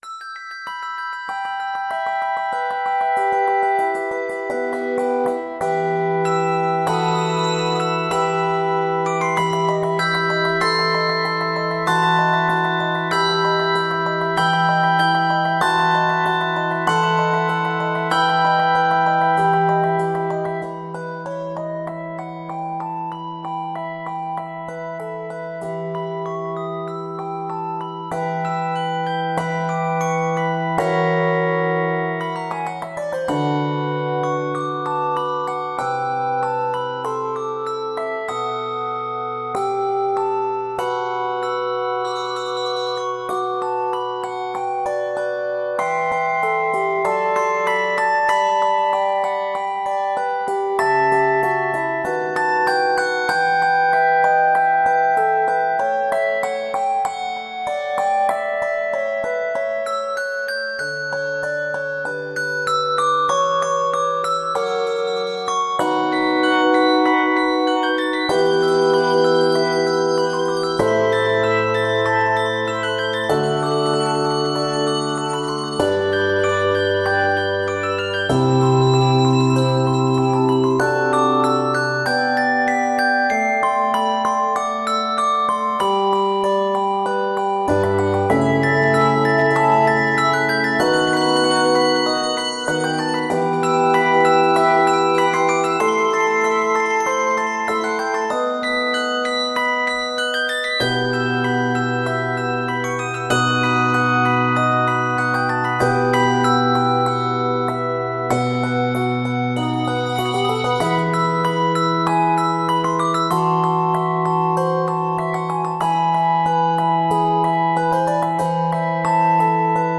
It remains true to the Baroque style of the composition.